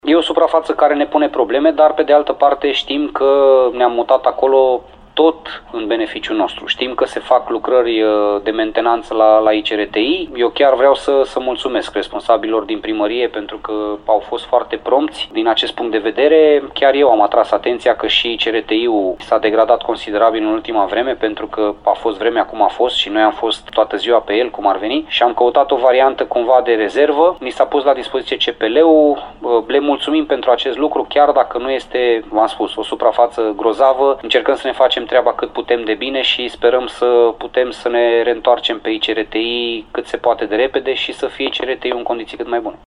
Antrenorul Laszlo Balint admite că nici la CPL nu e terenul grozav, dar salută intervenția la gazonul de pe ICRTI: